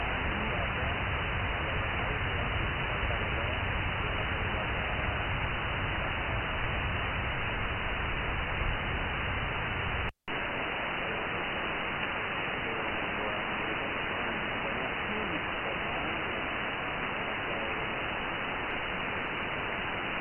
Audio comparisons between Airspy HF+ and Winradio G33DDC Excalibur Pro
No noise reduction (NR) was used.
Second 0-10 >> Winradio G33DDC Excalibur Pro
Second 10-20 >> Airspy HF+
21670KHz-AMS-Radio_Saudi.mp3